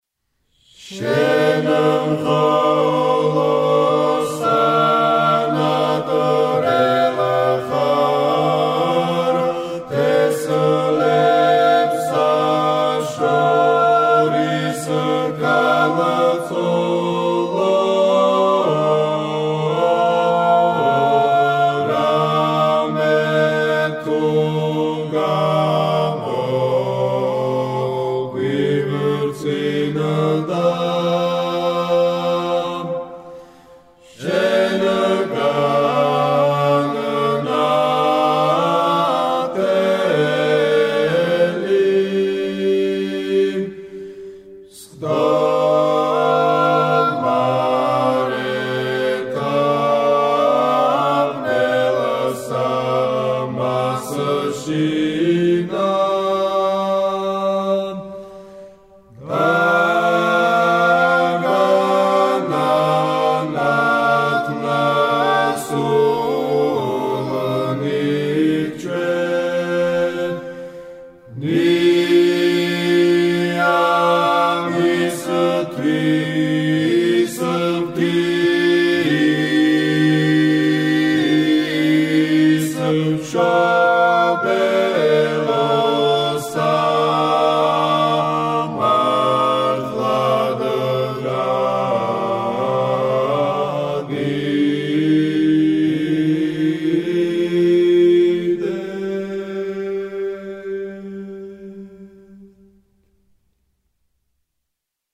საგალობელი
სკოლა: გელათის სკოლა